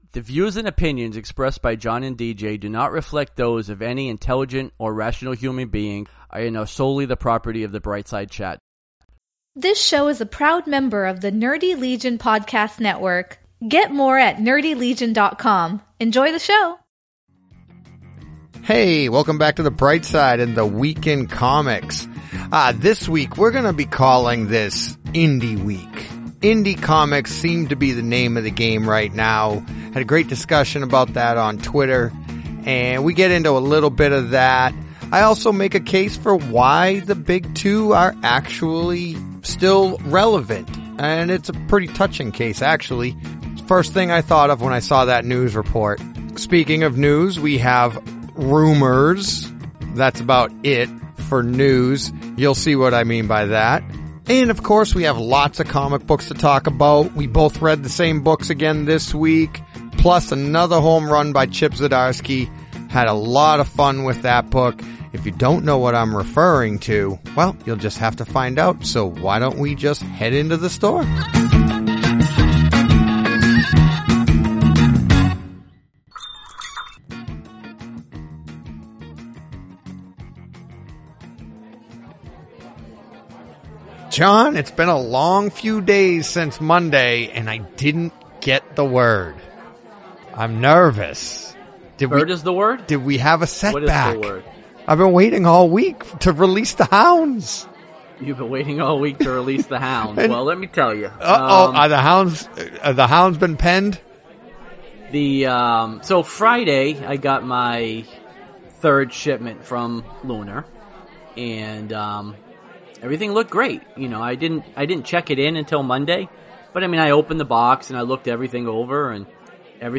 Then stop reading this and hit play and get into the store and listen to two guys that love talking all things comics.